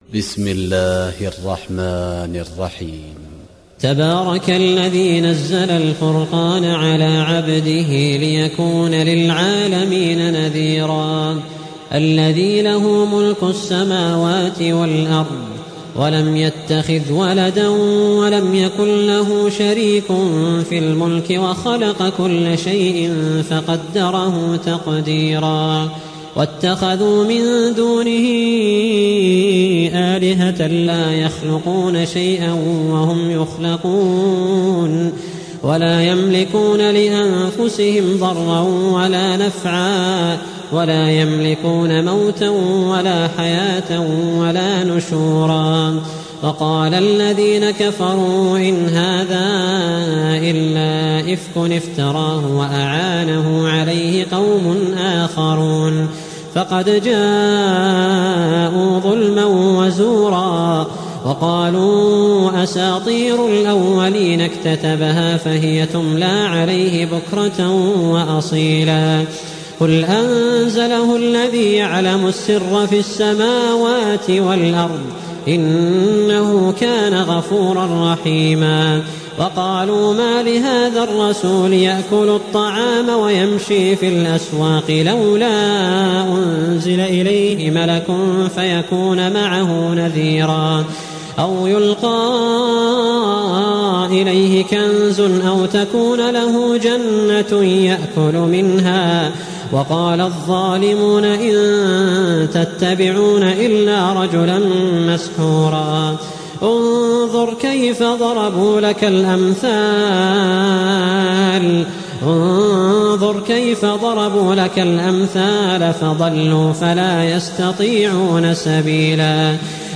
(روایت حفص)